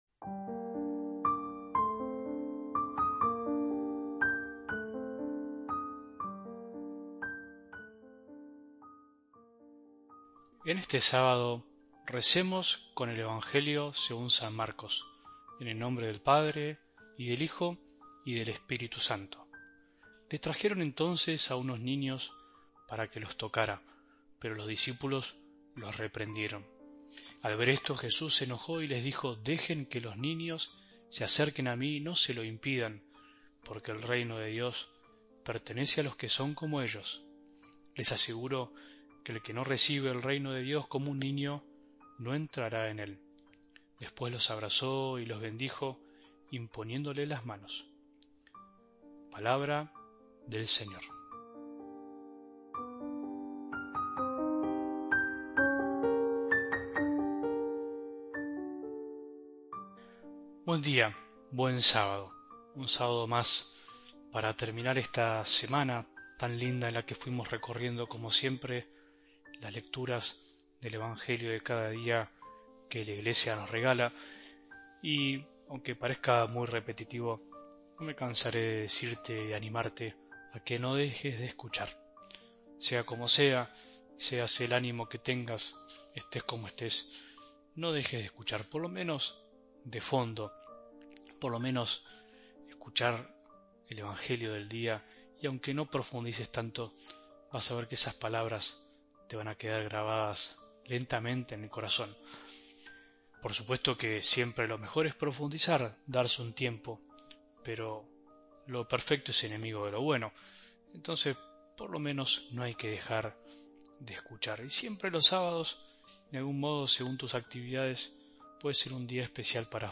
Oración 1 de Marzo 2025
Reflexión